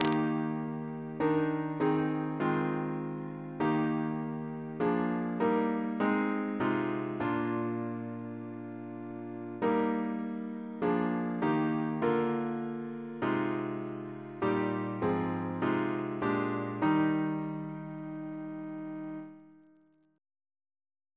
Double chant in E♭ Composer: Chris Biemesderfer (b.1958)